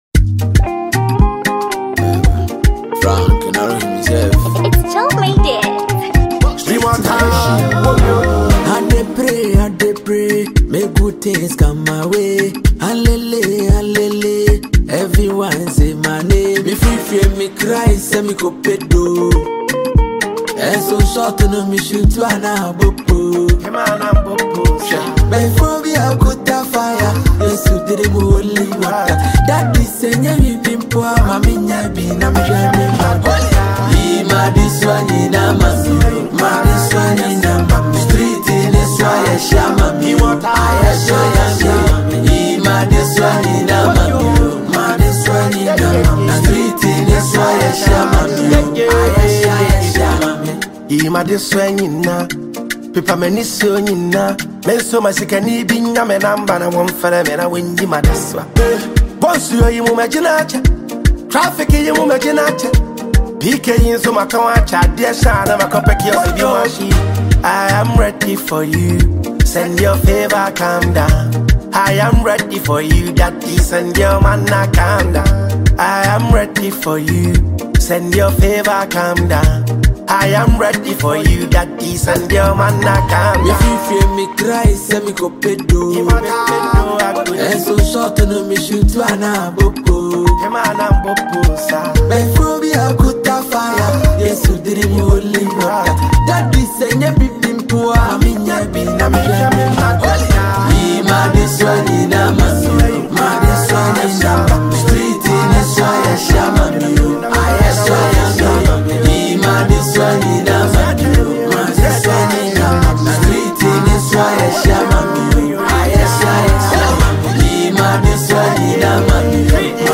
a Ghanaian highlife song featured Ghanaian musician